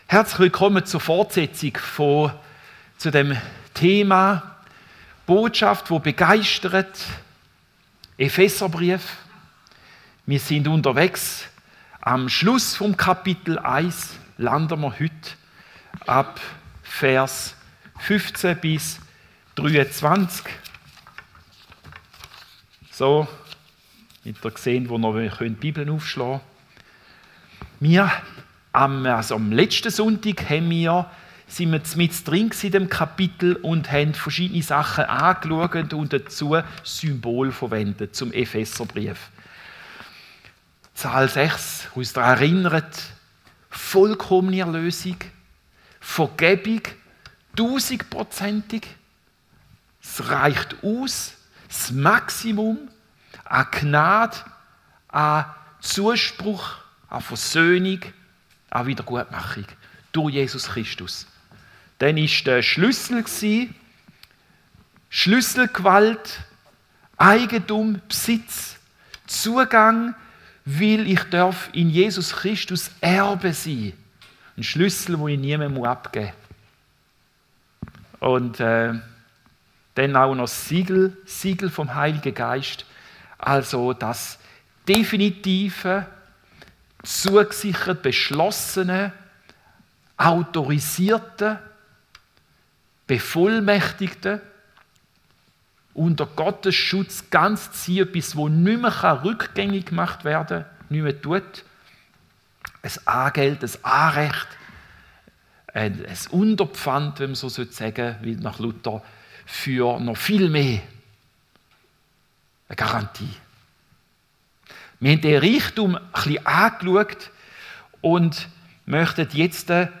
Predigt 9. Februar 2025